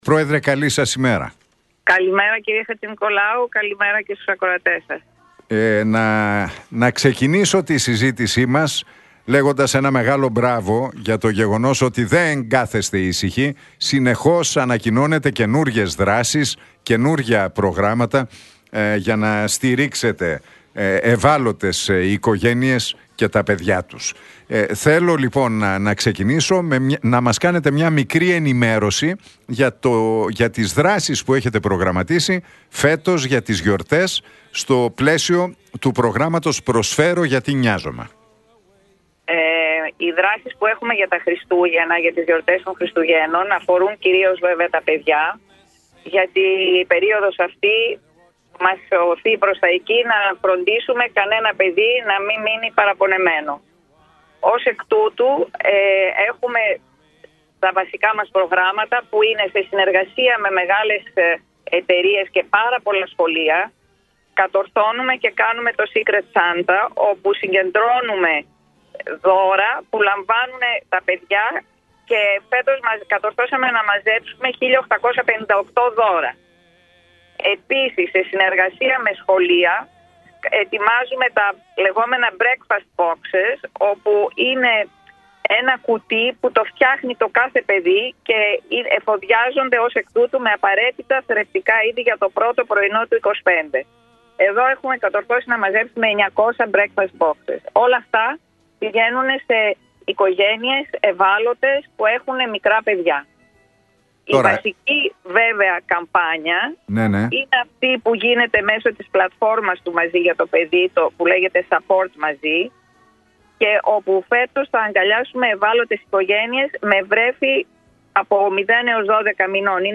στον Νίκο Χατζηνικολάου από τη συχνότητα του Realfm 97,8.